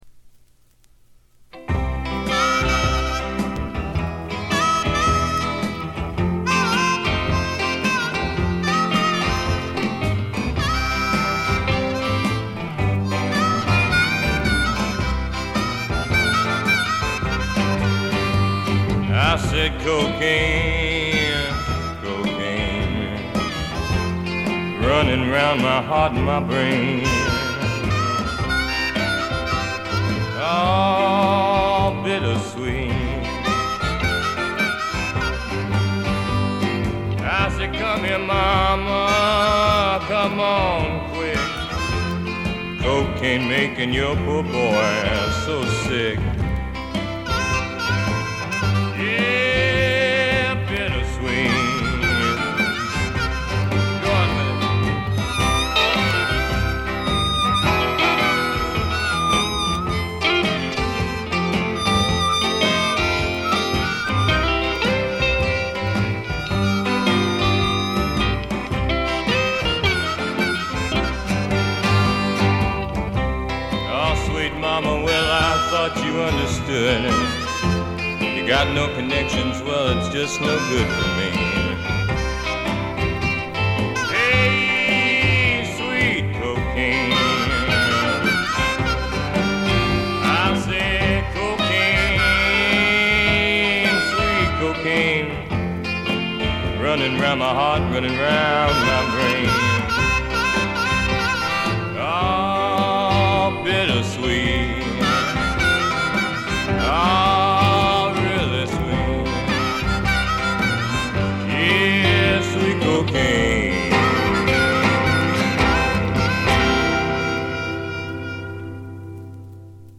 ごくわずかなノイズ感のみ。
言わずとしれた60年代を代表するフォーク／アシッド・フォークの大名作ですね。
地を這うように流れ出すヴォーカルには底なし沼の深淵に引きずり込まれるような恐怖とぞくぞくする快感を覚えます。
モノラル盤。
試聴曲は現品からの取り込み音源です。